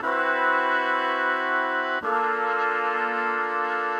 Index of /musicradar/gangster-sting-samples/120bpm Loops
GS_MuteHorn_120-C.wav